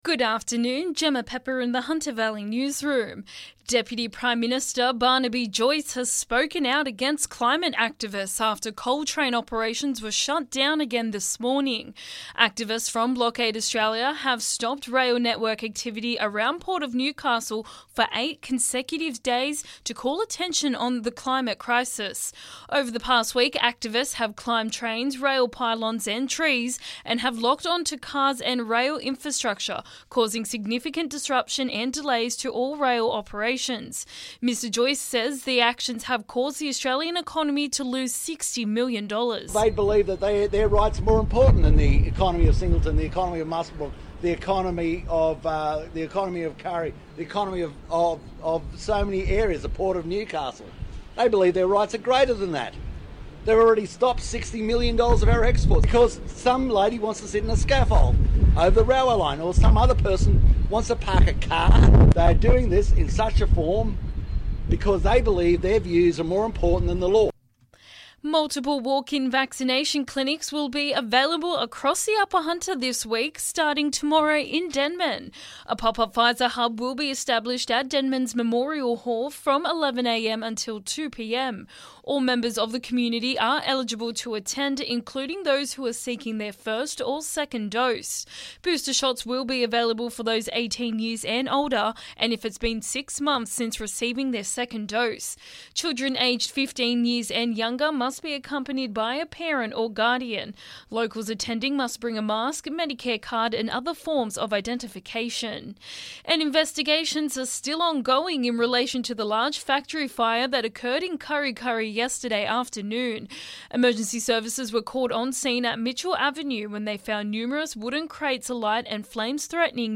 LISTEN: Hunter Valley Local News Headlines 15/11/2021